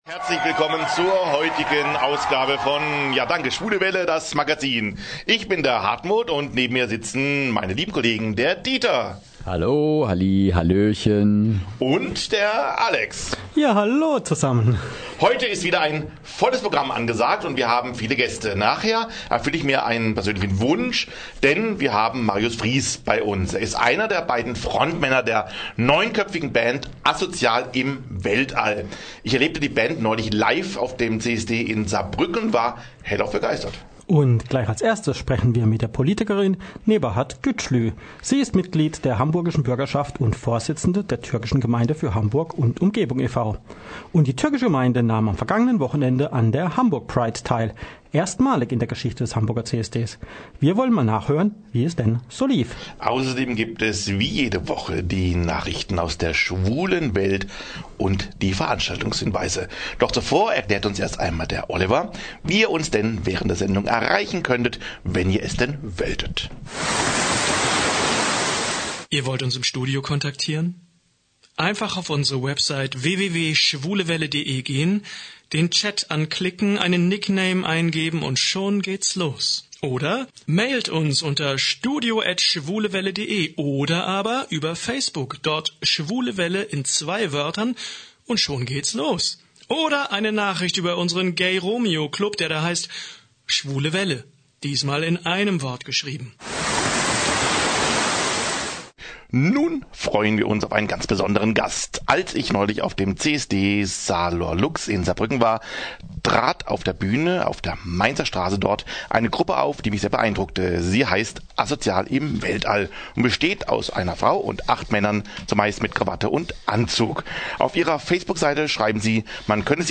In der 2. Ausgabe der Schwulen Show waren die Edlen Schnittchen zu Gast im Studio.
Aus urheberrechtlichen Gründen leider ohne die Musik, aber dafür die Interviews mit den sympathischen und witzigen Ladies und den Schwule Welle Korrespondenten auf dem Cannstatter Wasen direkt und pur.